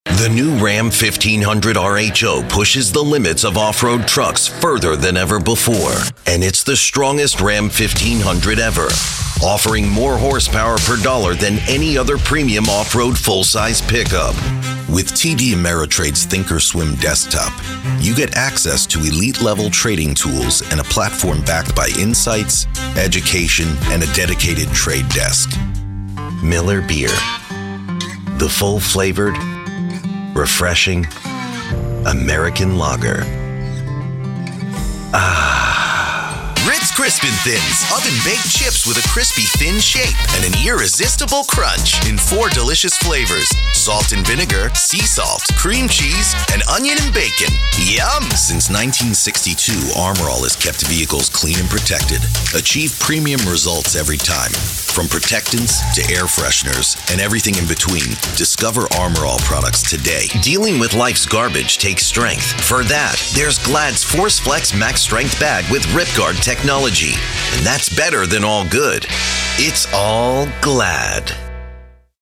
A friendly male voice with the ability to provide a strong retail and commercial voice for sales and promotion, trailers, video game and animated characters, e-books and e-learning, and so much more...
Commercial
Middle Aged
Full soundproof home studio